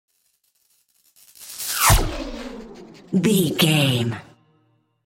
Whoosh sci fi disappear electricity
Sound Effects
dark
futuristic
whoosh